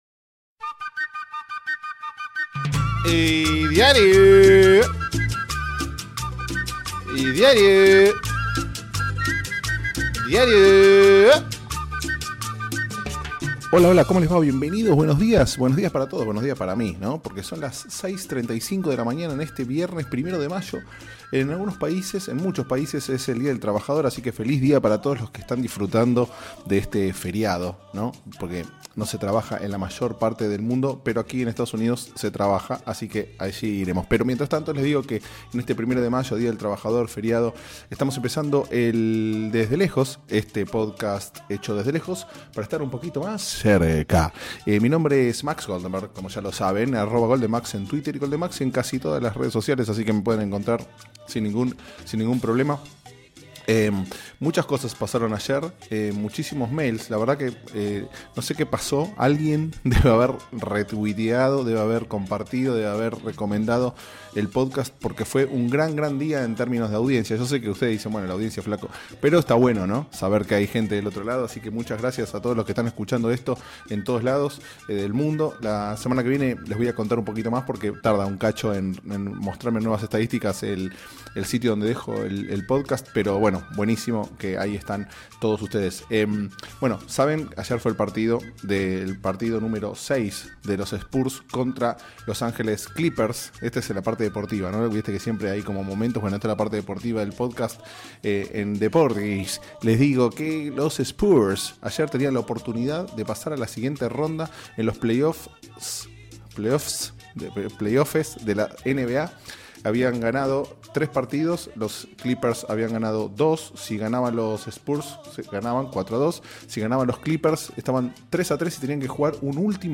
Podacast de viernes, con voz de dormido y todo.